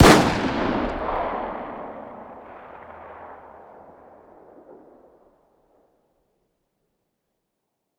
fire-dist-357sig-pistol-ext-05.ogg